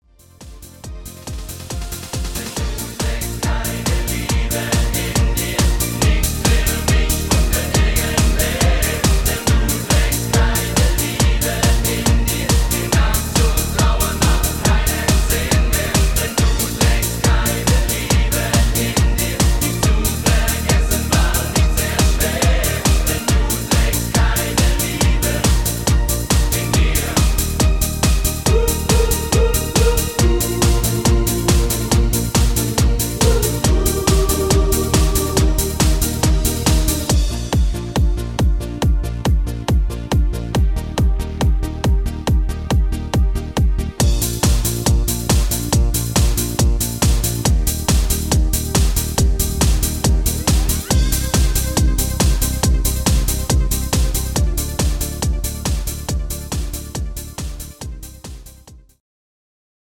Rhythmus  Party Disco
Art  Deutsch, Fasching und Stimmung, Party Hits